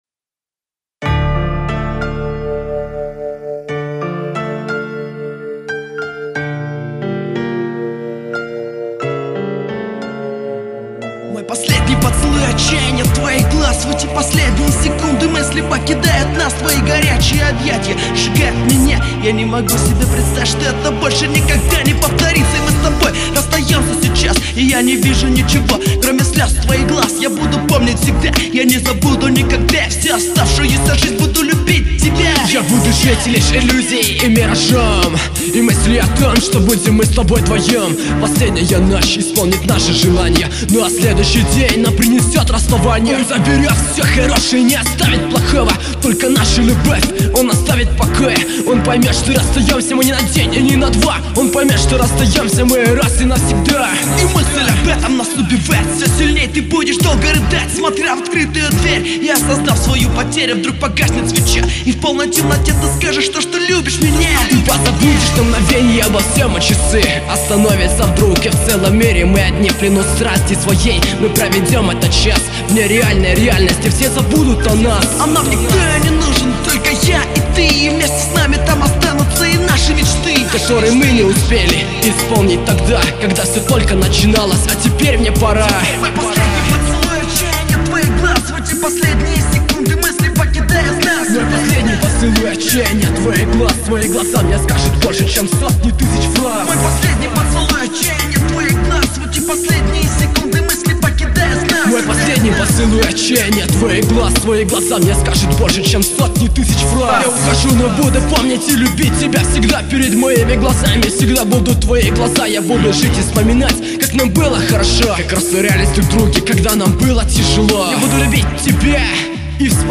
mp3,3894k] Рэп